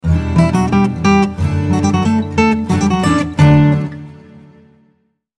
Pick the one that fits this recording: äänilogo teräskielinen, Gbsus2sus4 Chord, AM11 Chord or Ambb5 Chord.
äänilogo teräskielinen